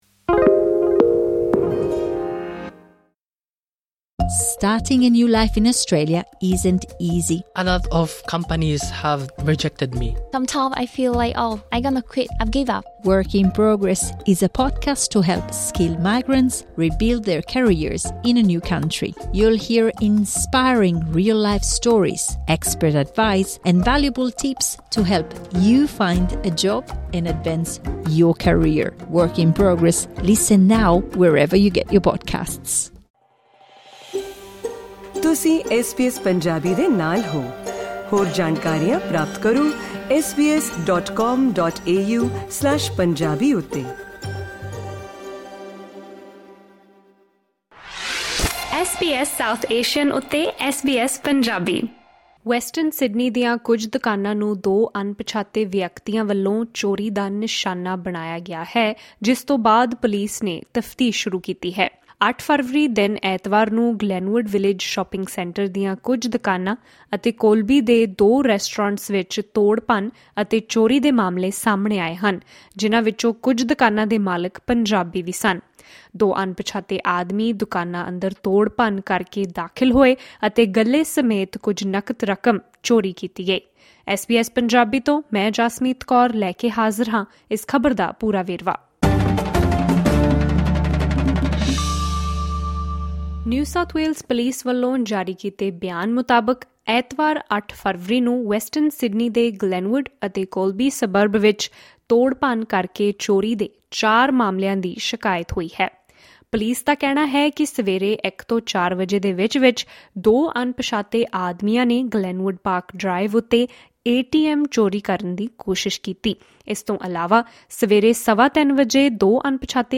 ਪੂਰੀ ਜਾਣਕਾਰੀ ਲਈ ਸੁਣੋ ਇਹ ਆਡੀਓ ਰਿਪੋਰਟ।